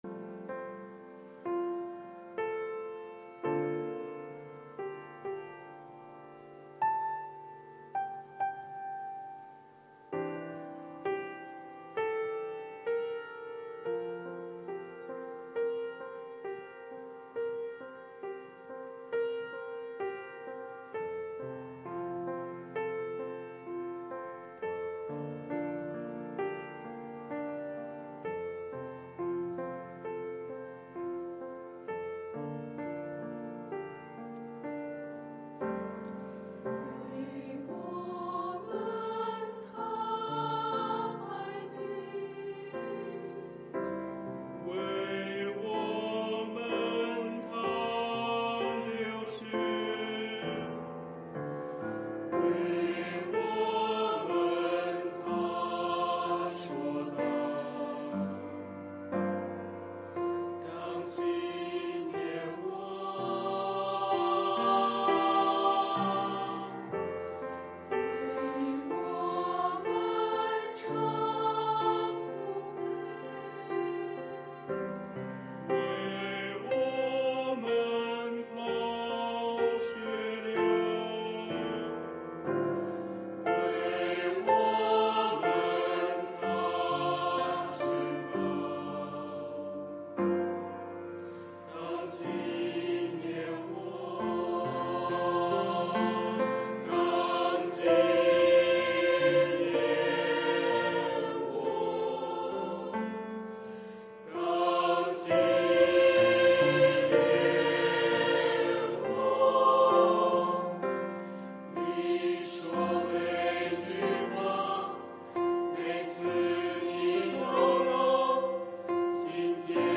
团契名称: 青年、迦密诗班
诗班献诗